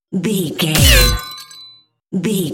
Dramatic hit metal electricity debris
Sound Effects
heavy
intense
dark
aggressive
hits